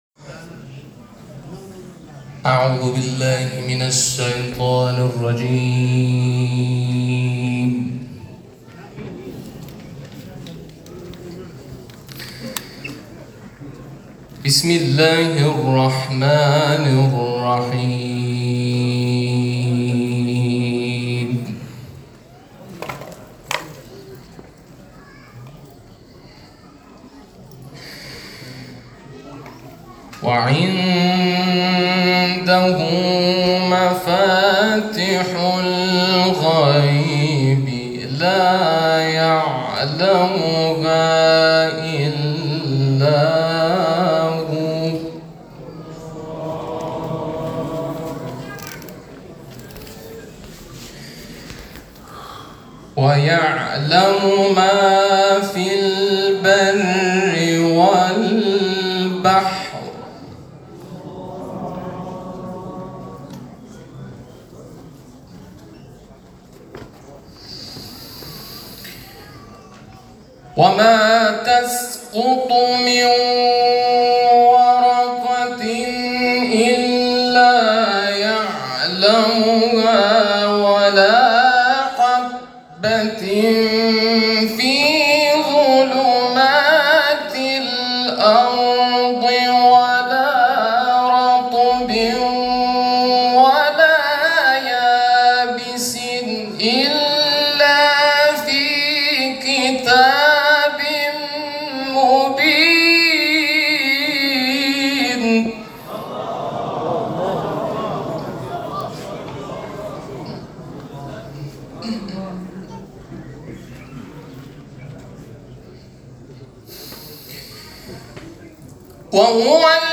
چهل و پنجمین دوره مسابقات سراسری قرآن